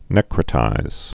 (nĕkrə-tīz)